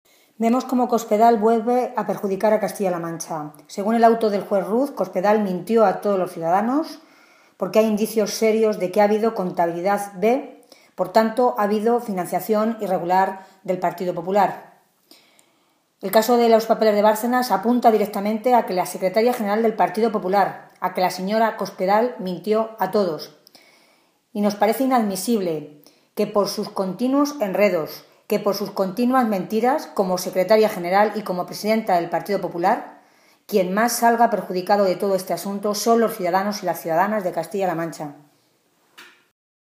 Tolón se pronunciaba de esta manera esta mañana en Toledo, en una comparecencia ante los medios de comunicación en la que hacía referencia al auto, conocido ayer, del juez de la Audiencia Nacional, Pablo Ruz, que establece indicios claros de que el PP ha estado manteniendo en el tiempo una doble contabilidad o contabilidad B y amplía el periodo a investigar hasta el año 2011, cuando Cospedal llevaba ya años siendo la número dos de este partido en España y después de que, según ella, los populares hubieran apartado a su ex tesorero, Luis Bárcenas, de cualquier responsabilidad.
Cortes de audio de la rueda de prensa